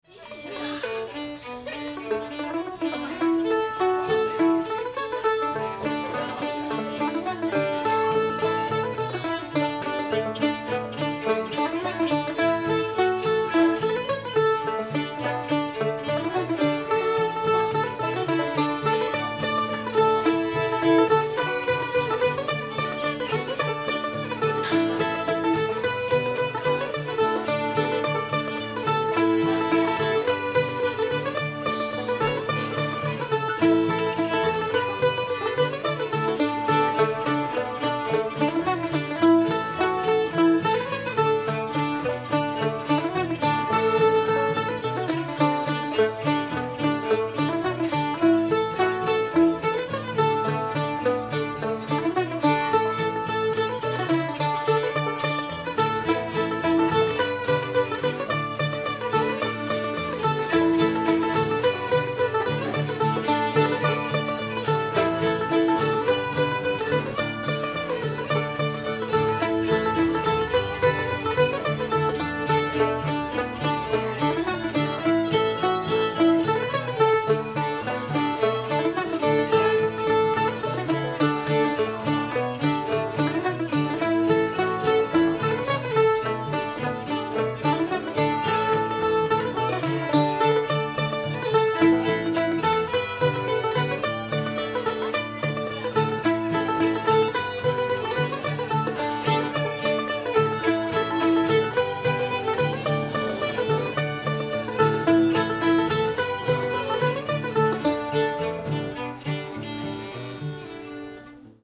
sarah armstrong's tune [D]